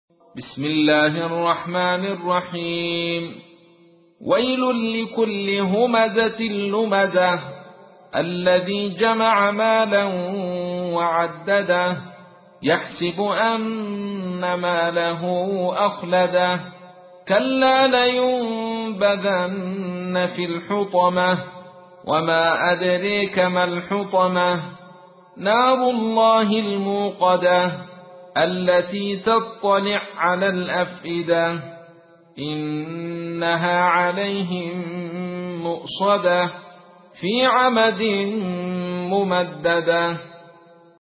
تحميل : 104. سورة الهمزة / القارئ عبد الرشيد صوفي / القرآن الكريم / موقع يا حسين